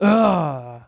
Male_Grunt_Attack_04.wav